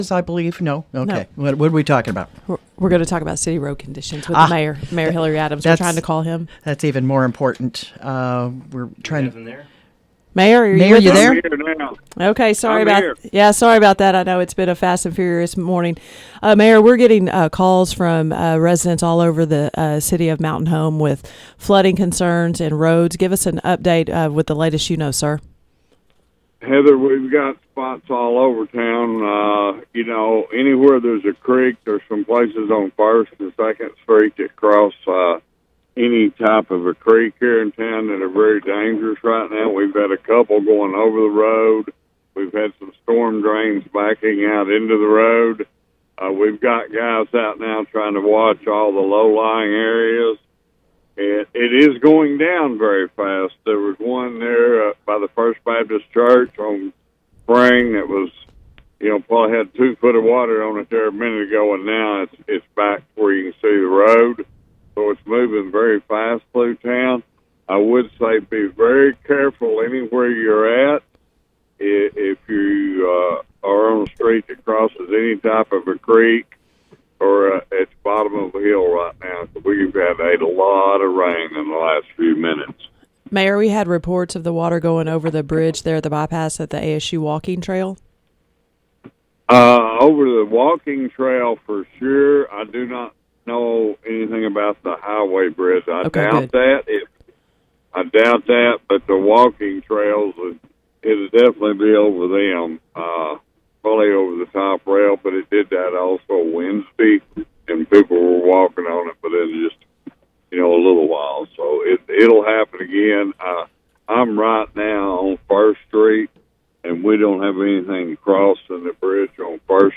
Mountain Home Mayor Hillrey Adams spoke with KTLO News this morning to give an update on weather conditions.